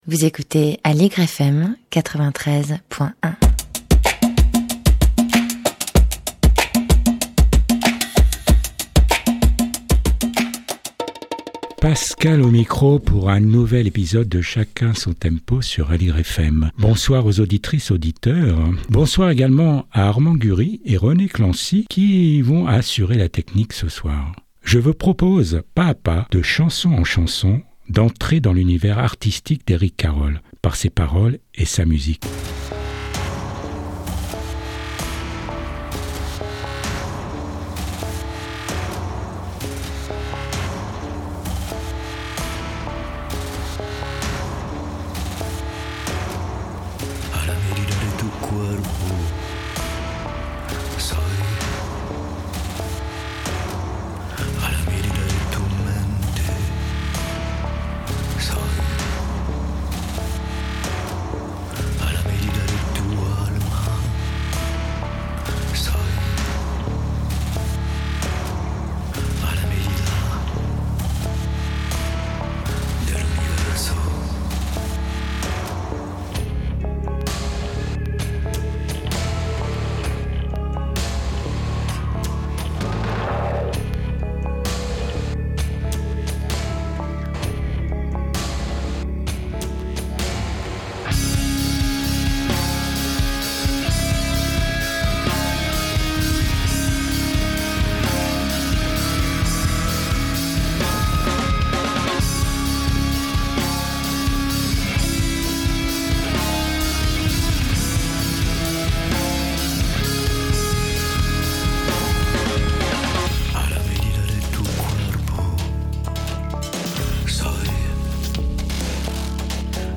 Portrait radiophonique